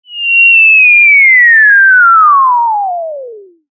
Falling Sound Effect
Cartoon long falling down.
Falling-sound-effect.mp3